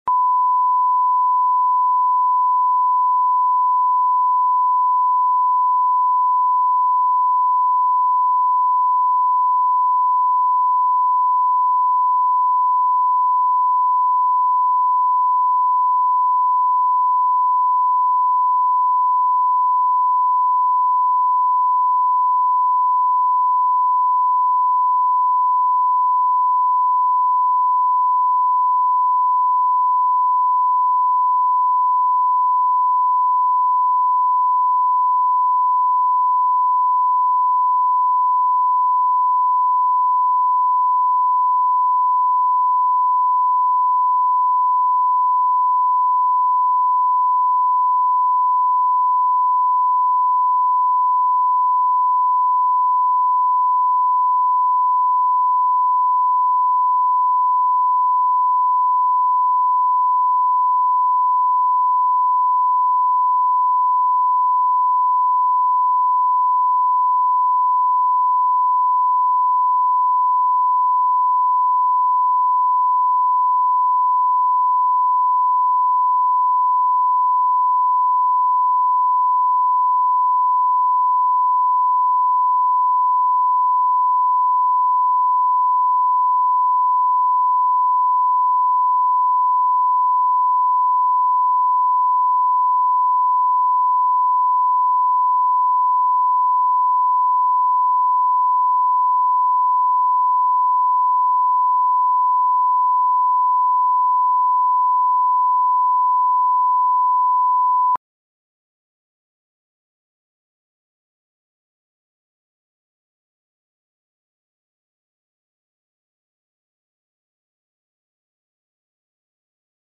Аудиокнига Редкий экземпляр